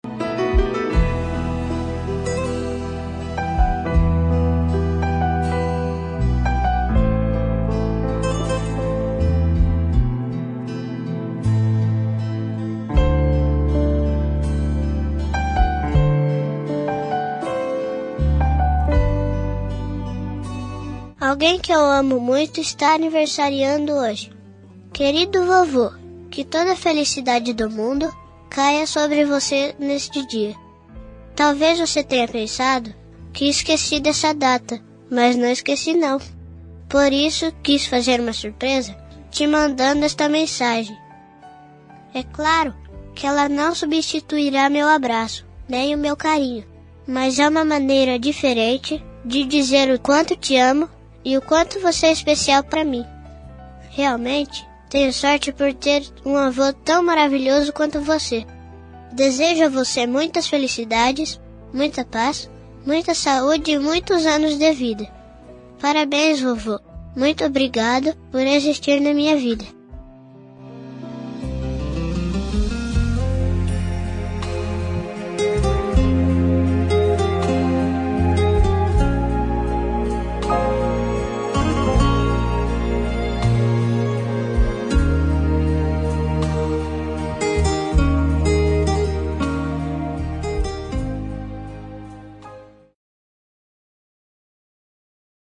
Aniversário Voz Infantil – Avô – Voz Masculina – Cód: 258312